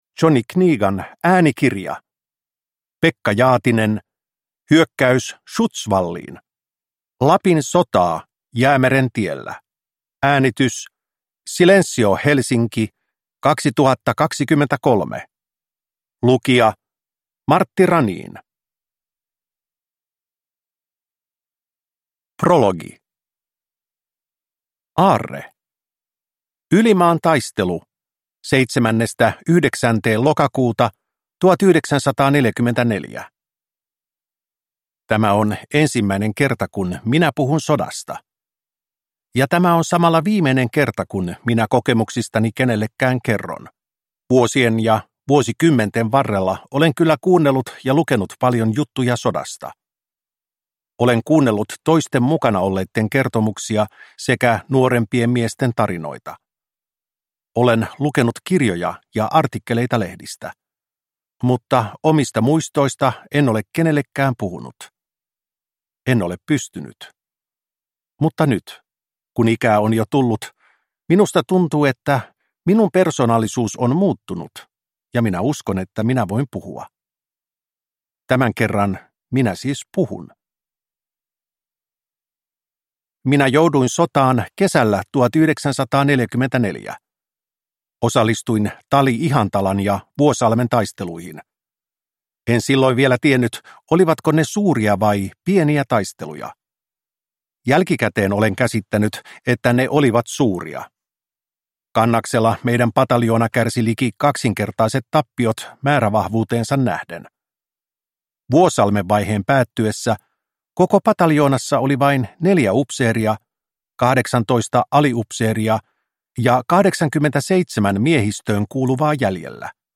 Hyökkäys Schutzwalliin – Ljudbok